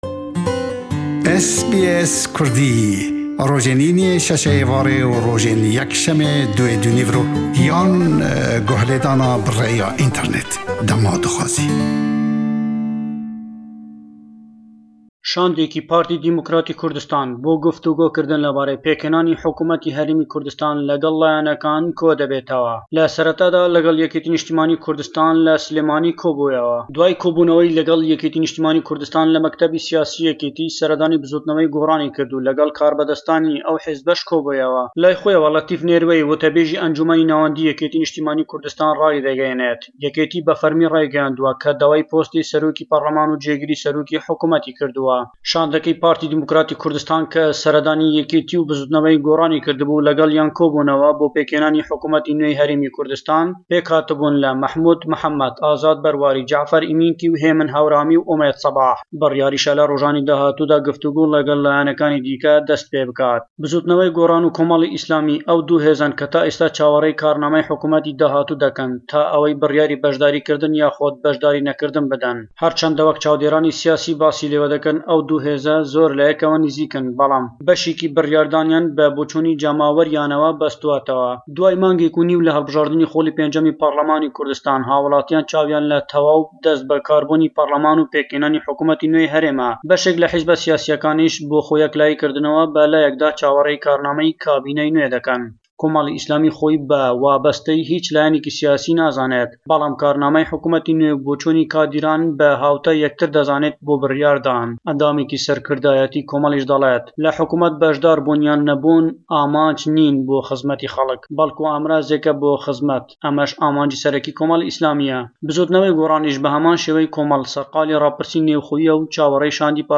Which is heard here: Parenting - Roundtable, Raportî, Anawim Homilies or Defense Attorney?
Raportî